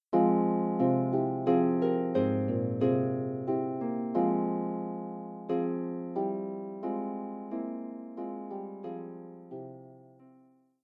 arranged for solo lever or pedal harp